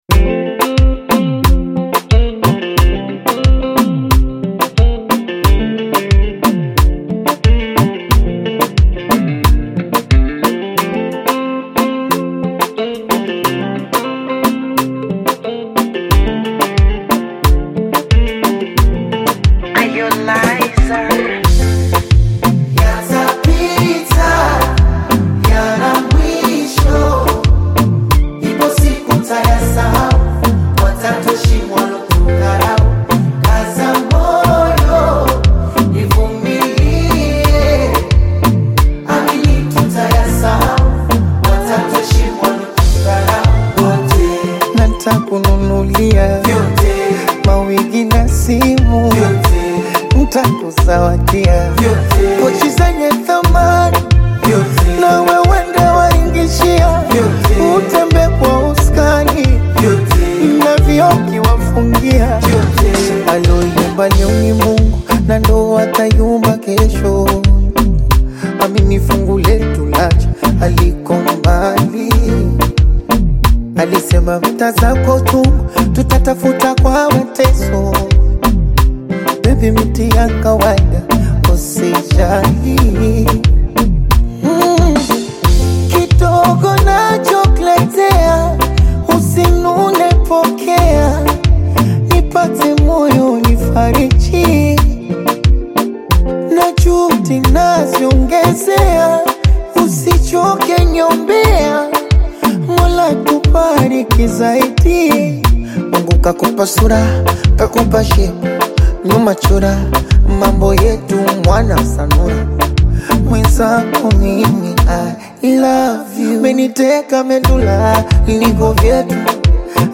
Afro-Pop single
Genre: Bongo Flava